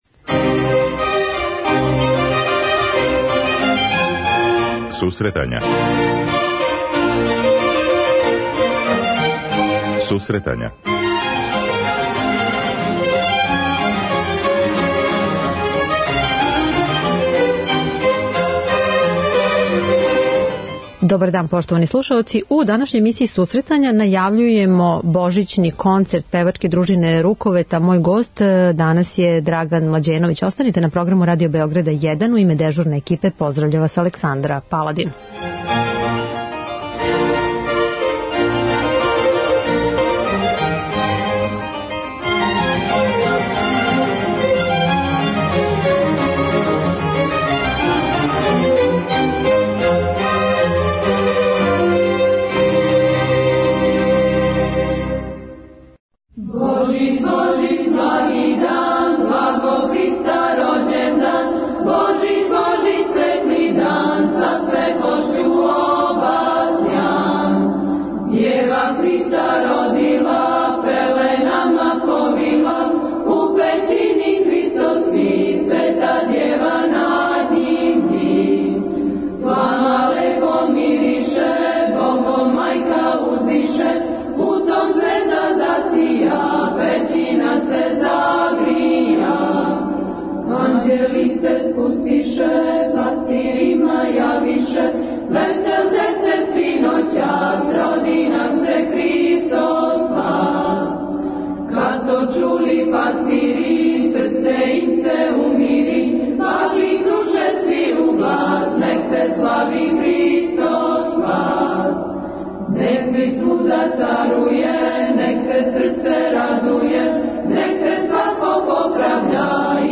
преузми : 10.77 MB Сусретања Autor: Музичка редакција Емисија за оне који воле уметничку музику.